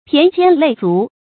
骈肩累足 pián jiān lèi zú
骈肩累足发音
成语注音 ㄆㄧㄢˊ ㄐㄧㄢ ㄌㄟˇ ㄗㄨˊ